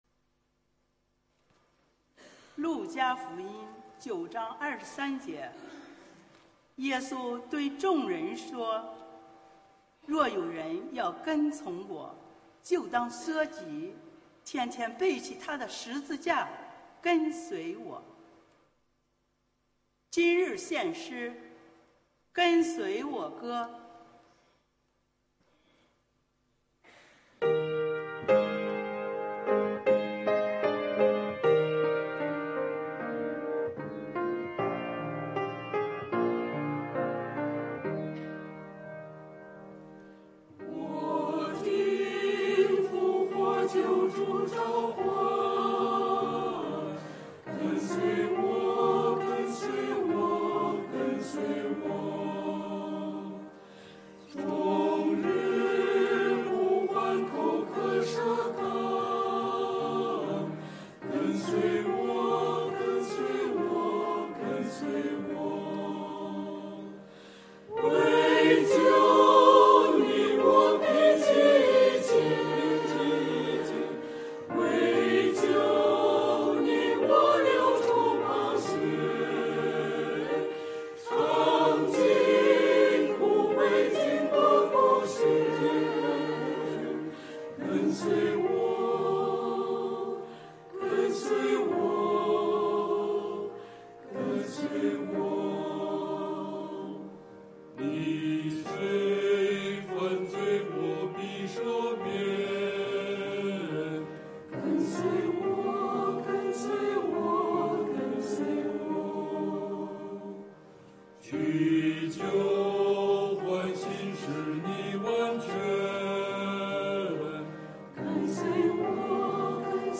[2023年5月28日主日献唱]《跟随我歌》 | 北京基督教会海淀堂
团契名称: 联合诗班 新闻分类: 诗班献诗 音频: 下载证道音频 (如果无法下载请右键点击链接选择"另存为") 视频: 下载此视频 (如果无法下载请右键点击链接选择"另存为")